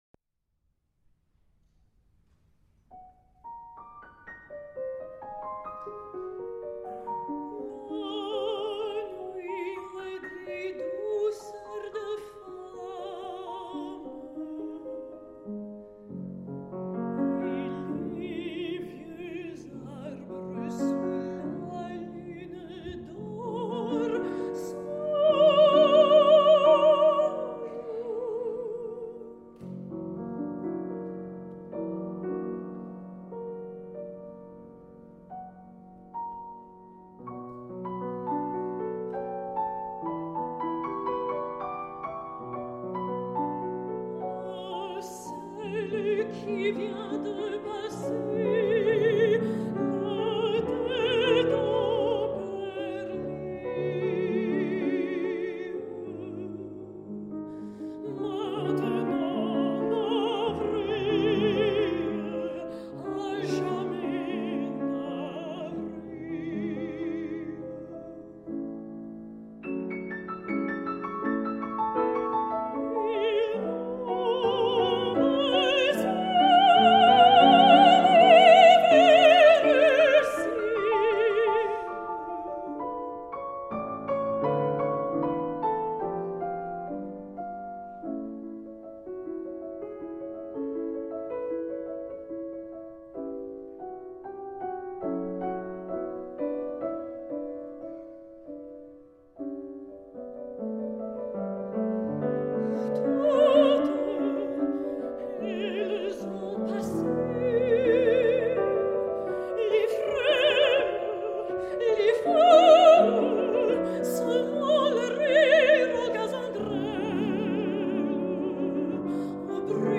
Recorded live at Wigmore Hall, London
Soprano
Piano